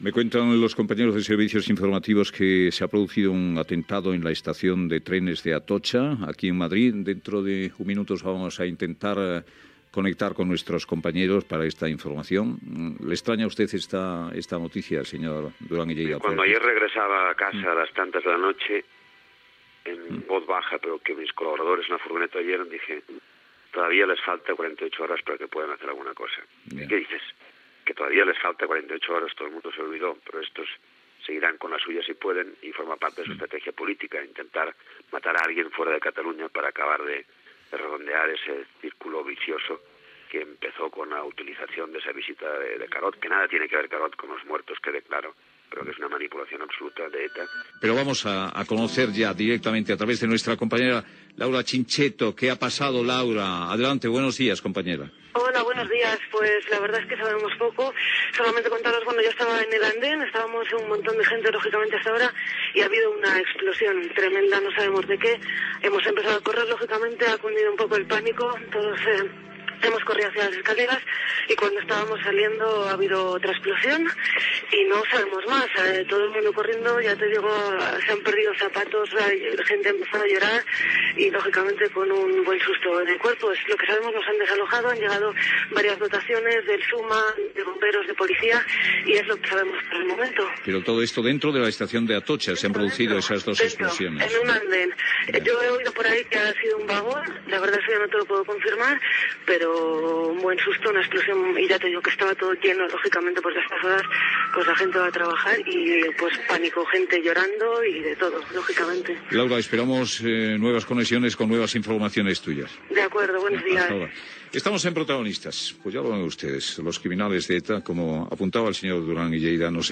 88c547f6c5934b261cdfe3fdd1ccf3c229114e31.mp3 Títol Onda Cero Radio Emissora Onda Cero Barcelona Cadena Onda Cero Radio Titularitat Privada estatal Nom programa Protagonistas Descripció Fragment d'una entrevista a Josep Antoni Duran i Lleida i primeres notícies de l'atemptat de l'11 de març de 2004 a Madrid a l'estació d'Atocha. Resum de les informacions que es van anar donat al programa amb la intervenció d'Alberto Ruiz Gallardón, alcalde de Madrid
Info-entreteniment